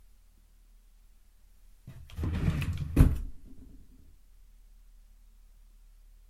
Fast/Open Drawer 4
Duration - 6 s Environment - Bedroom, absorption of curtains, carpet and bed. Description - Open slightly faster speed, pulled, grabs, slams, wooden drawer, bangs as it opens to the end